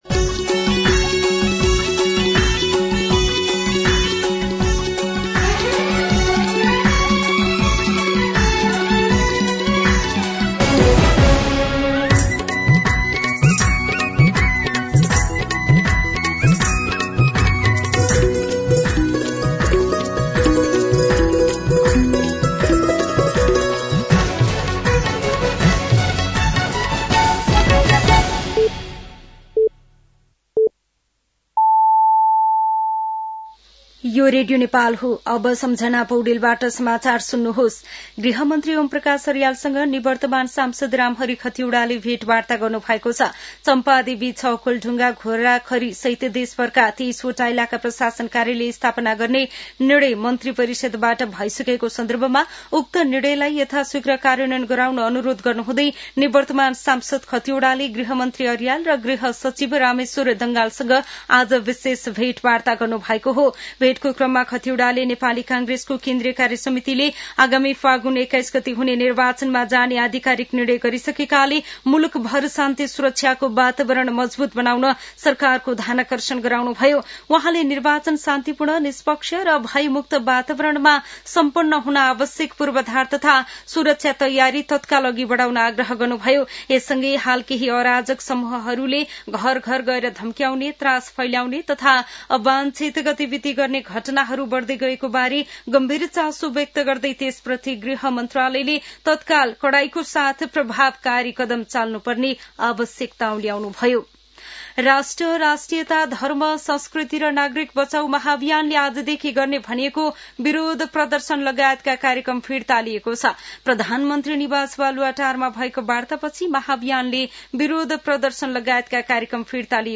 दिउँसो १ बजेको नेपाली समाचार : ७ मंसिर , २०८२